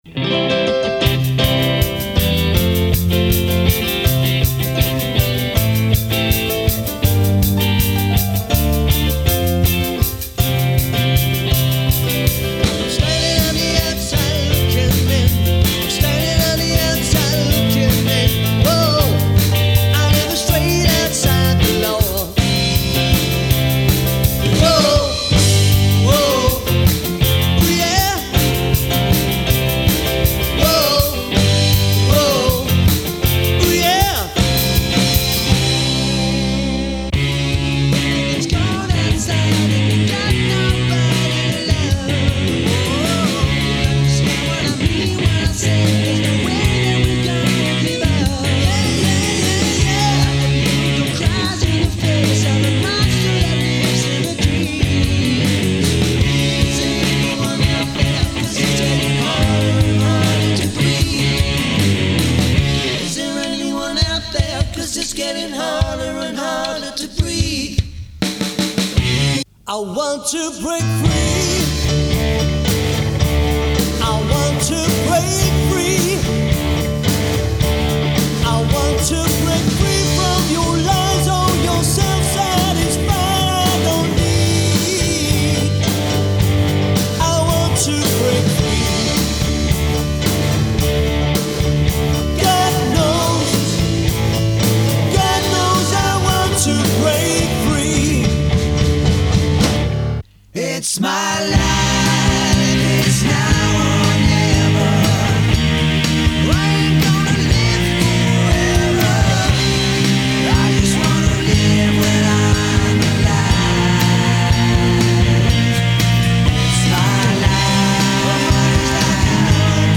high-energy four-piece rock/pop cover band.
Lead/Backing Vocals & Guitar UK born and bred